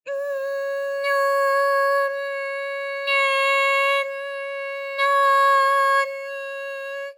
ALYS-DB-001-JPN - First Japanese UTAU vocal library of ALYS.